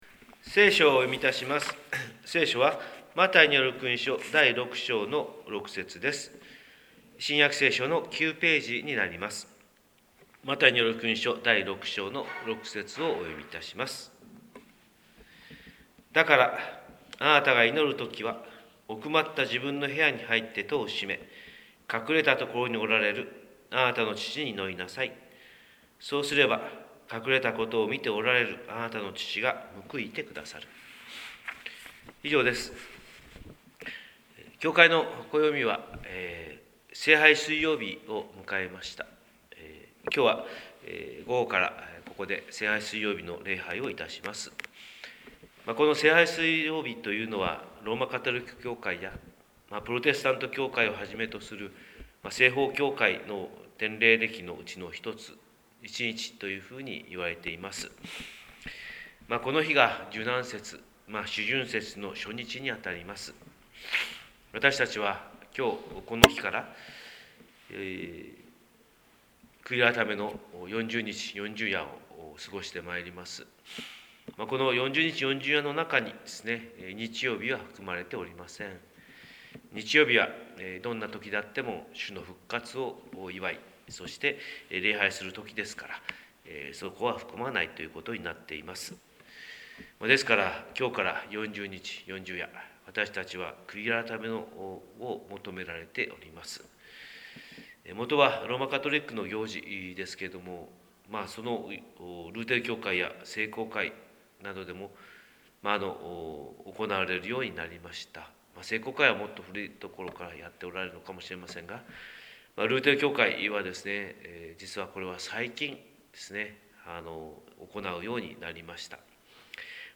神様の色鉛筆（音声説教）: 広島教会朝礼拝250305